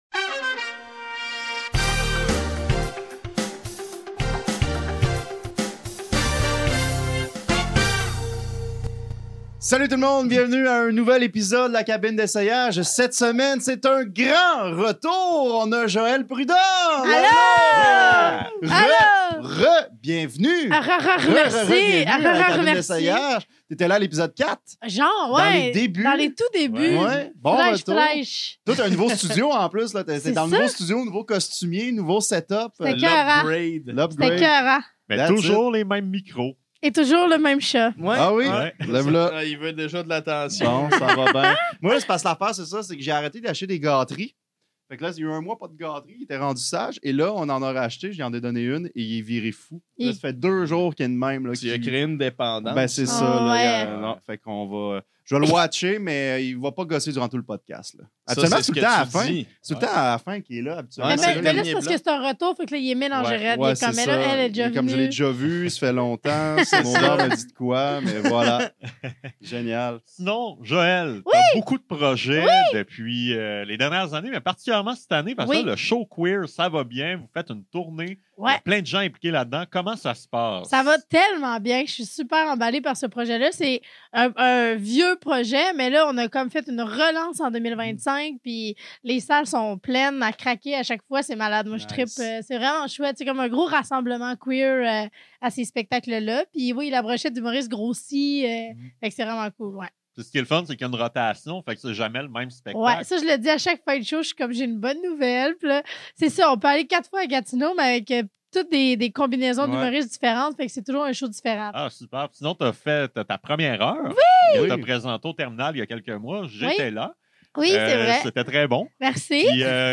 À chaque épisode, Les Piles-Poils et un artiste invité doivent présenter un court numéro sous forme de personnage (ou de stand-up) à partir d’un thème pigé au hasard.